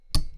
click3.wav